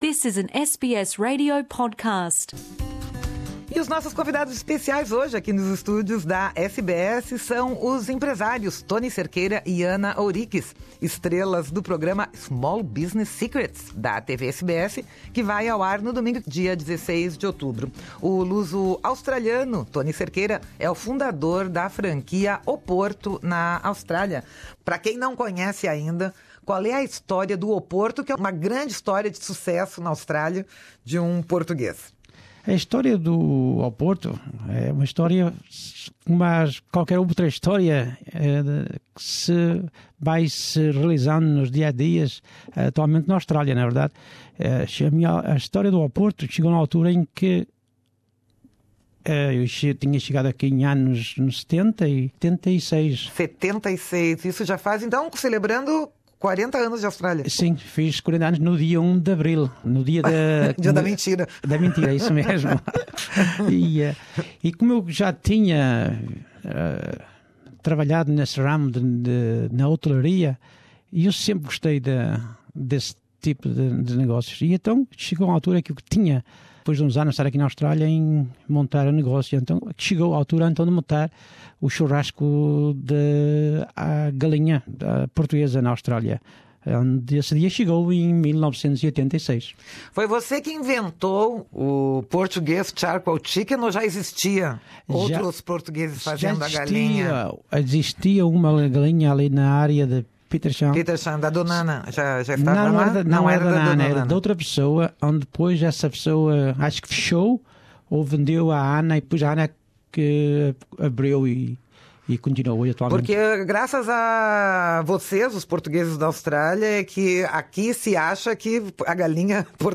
no Programa Português da Rádio SBS